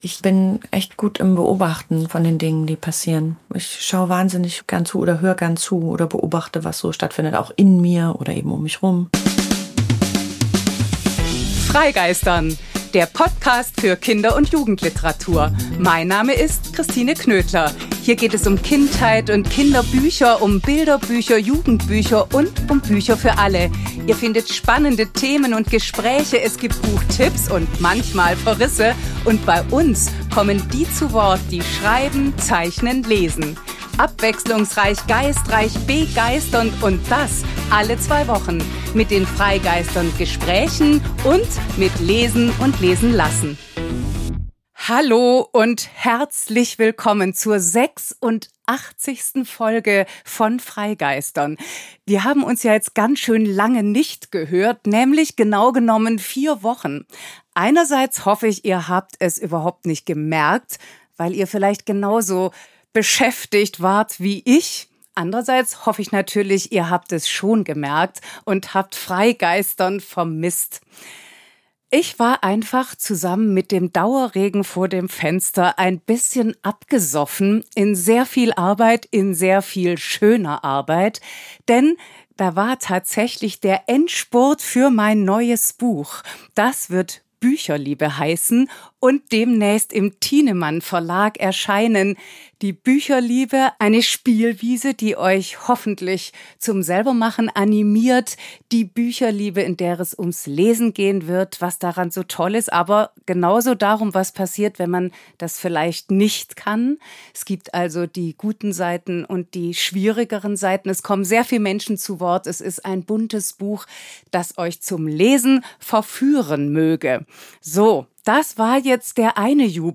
Ein Gespräch über Kino, Filme, Theater, Bücher, über das Schauspielen, das Erzählen, das Lesen, über Worte, die stärken, und Sätze, die da sind. Ein Gespräch über Ost, West, über Geschichte, Geschichten, Narrative. Ein Gespräch über Sprache, die gewaltsame und die achtsame, und darüber, wie sie Realität schafft.